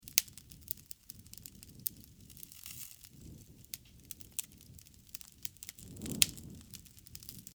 環境音 | 無料 BGM・効果音のフリー音源素材 | Springin’ Sound Stock
焚き火ループ.mp3